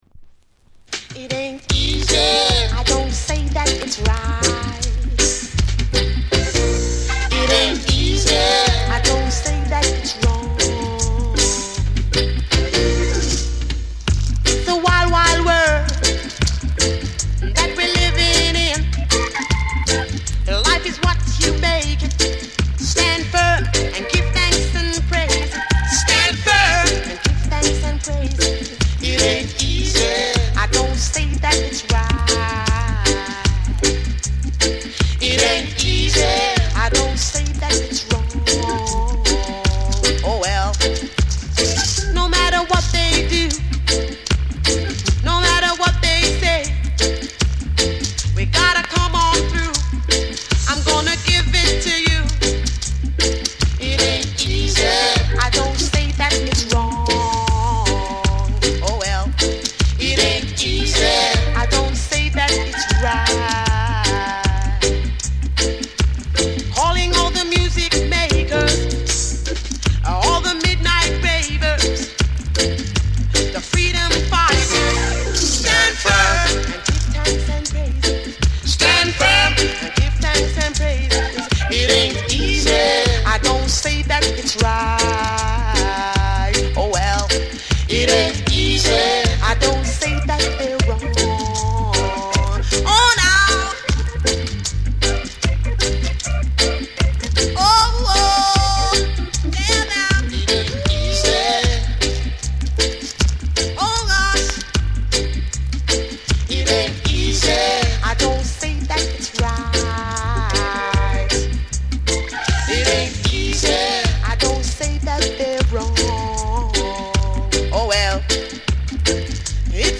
ミッドテンポのルーツ・リディムに乗せて、どこか儚げでメロウなハーモニーが心に染みる
REGGAE & DUB